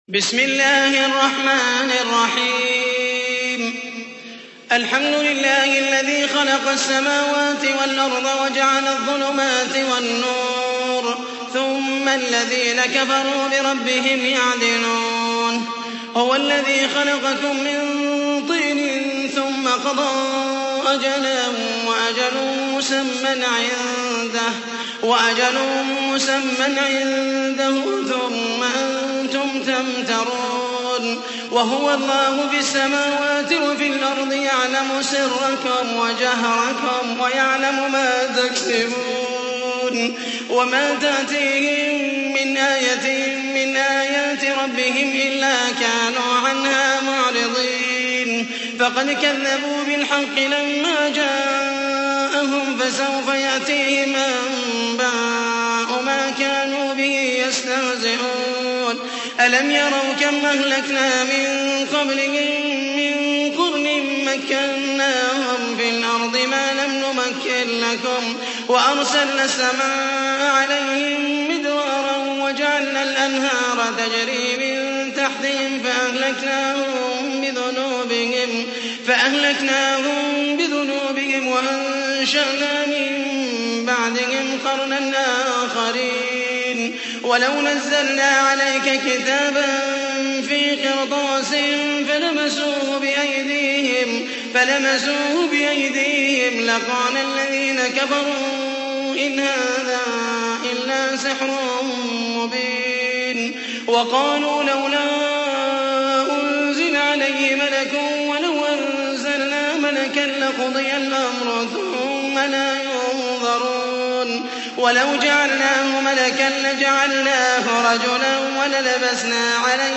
تحميل : 6. سورة الأنعام / القارئ محمد المحيسني / القرآن الكريم / موقع يا حسين